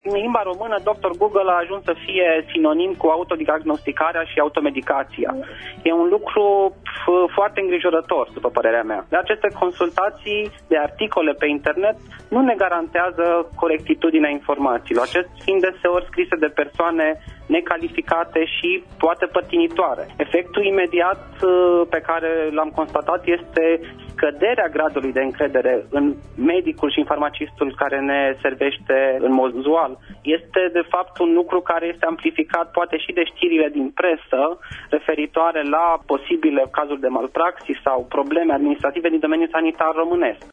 extras emisiunea „Pulsul zilei”